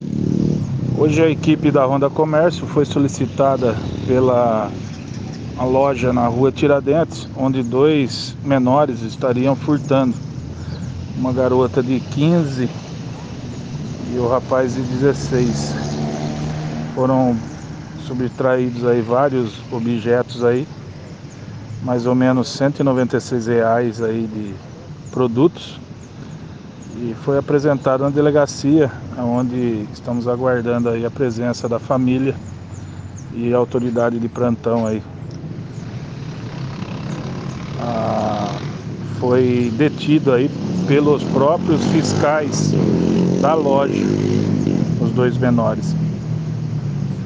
O repórter policial